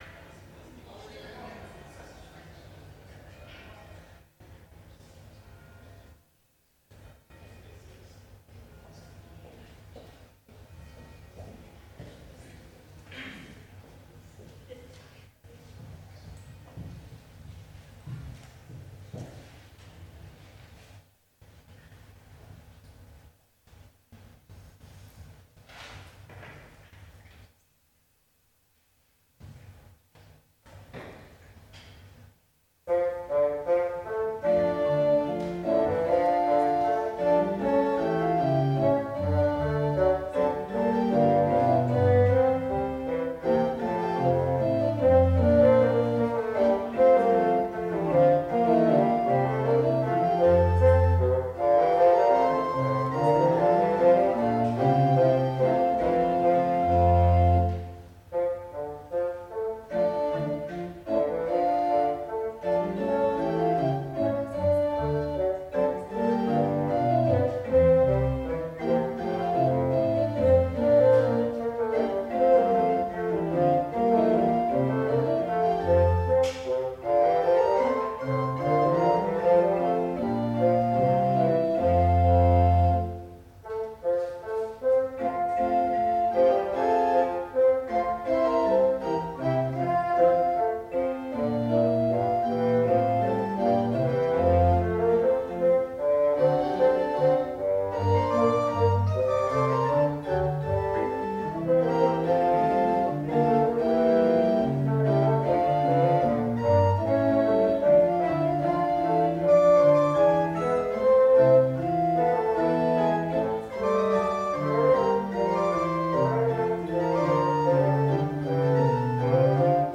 Gottesdienst - 13.07.2025 ~ Peter und Paul Gottesdienst-Podcast Podcast